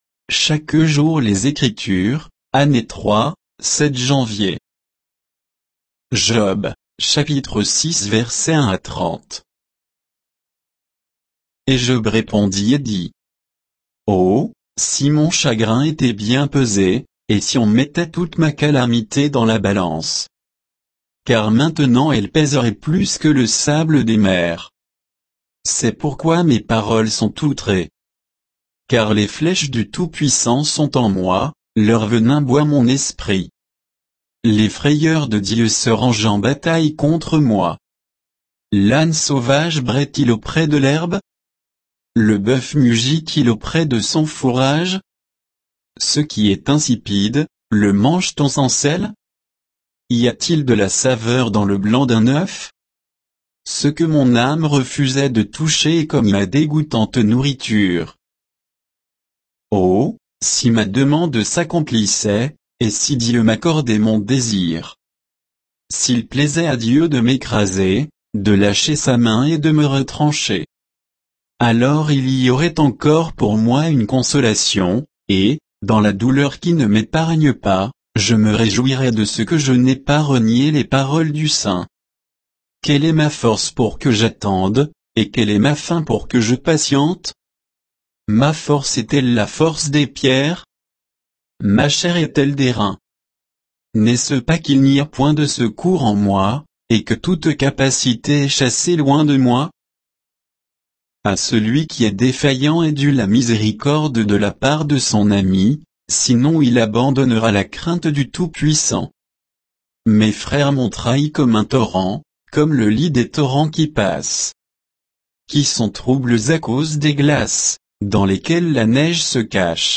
Méditation quoditienne de Chaque jour les Écritures sur Job 6, 1 à 30